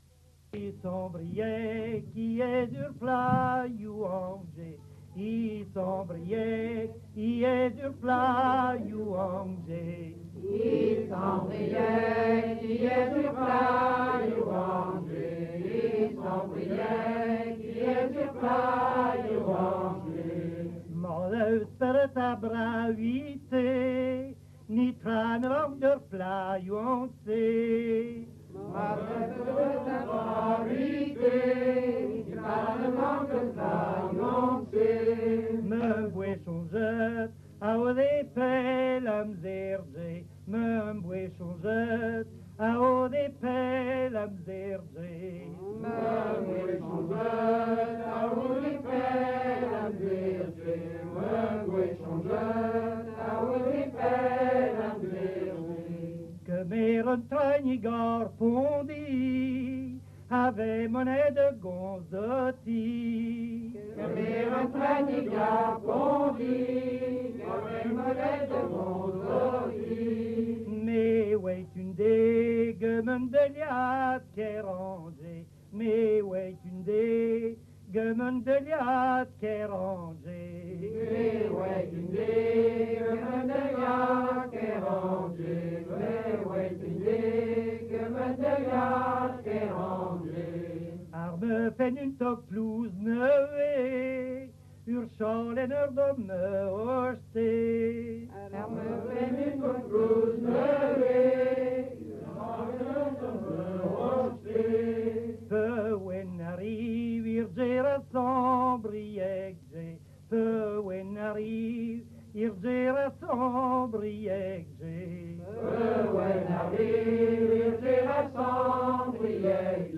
Sonennoù / Chansons Collecté en